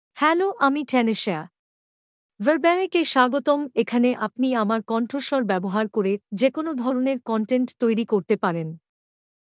FemaleBengali (India)
Tanishaa — Female Bengali AI voice
Tanishaa is a female AI voice for Bengali (India).
Voice sample
Female